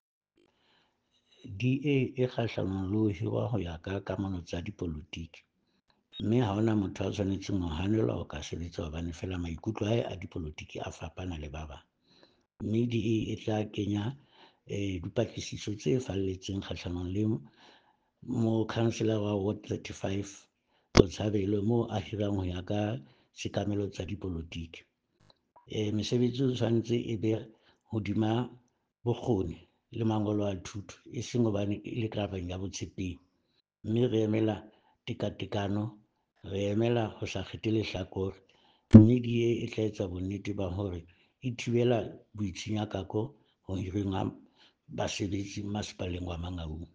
Sesotho soundbite by Cllr Mosala Matobole and